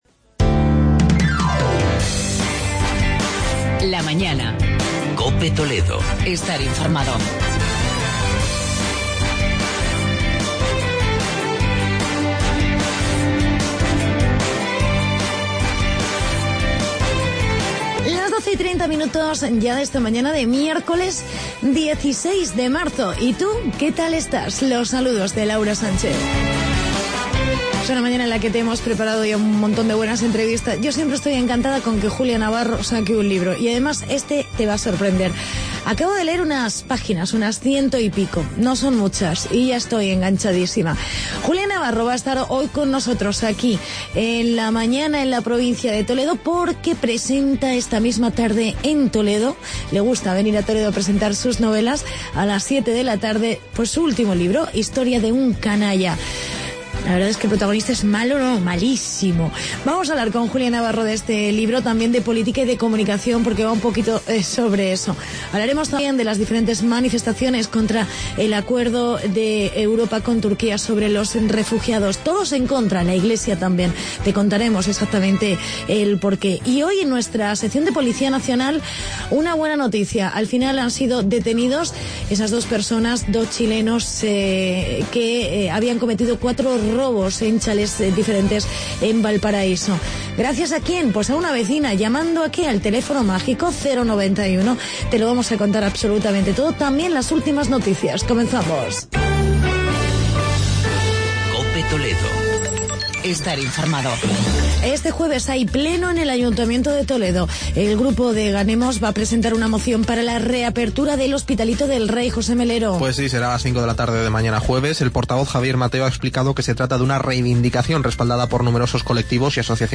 Entrevista con la escritora Julia Navarro.